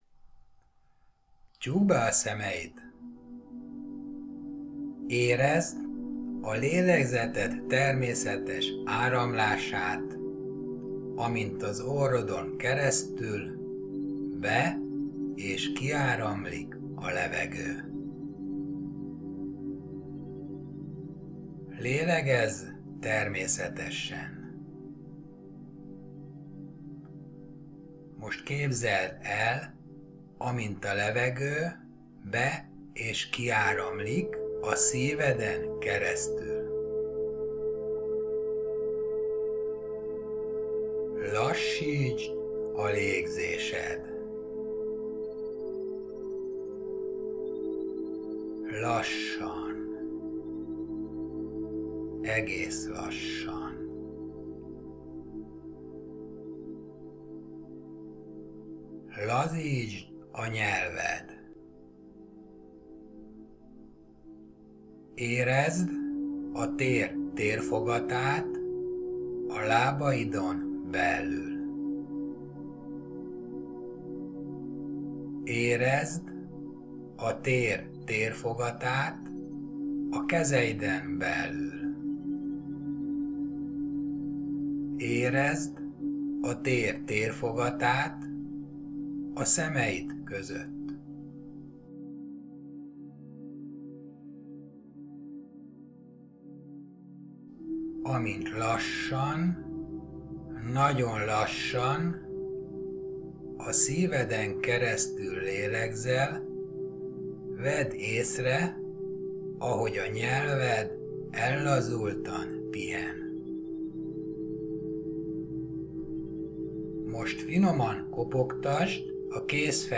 MEDITÁCIÓ 1.